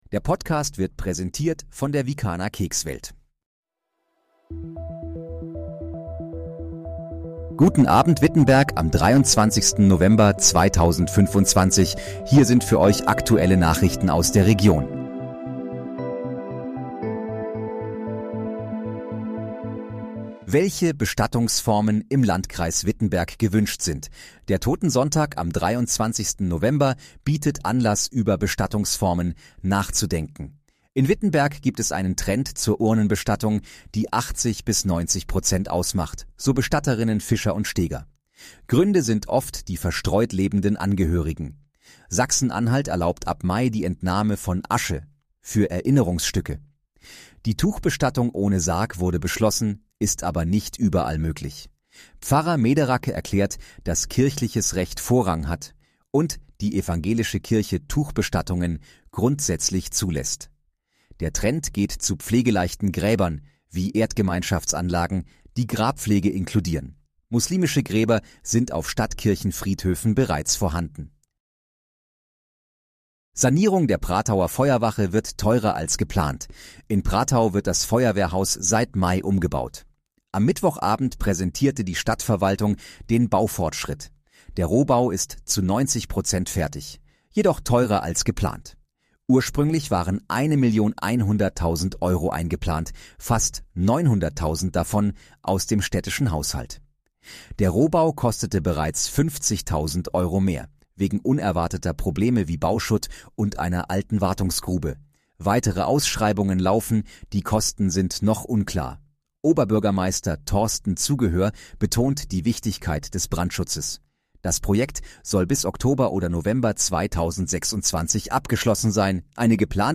Guten Abend, Wittenberg: Aktuelle Nachrichten vom 23.11.2025, erstellt mit KI-Unterstützung
Nachrichten